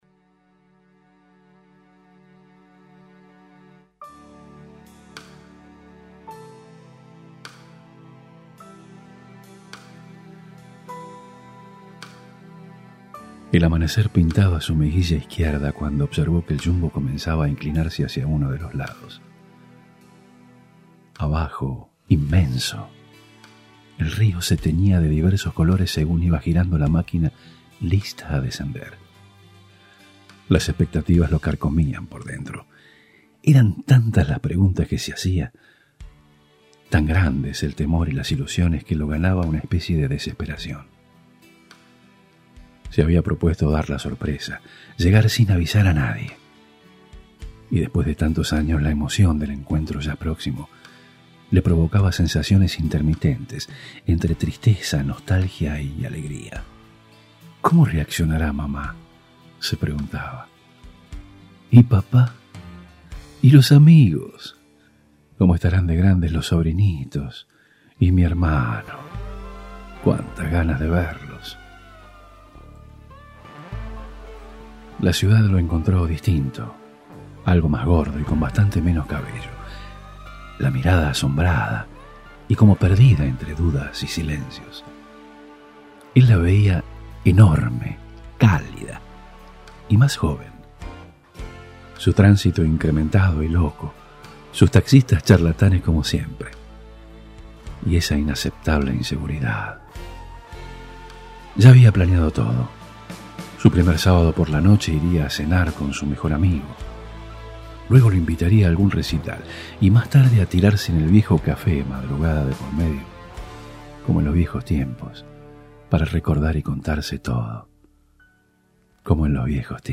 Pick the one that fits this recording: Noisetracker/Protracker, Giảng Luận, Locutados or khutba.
Locutados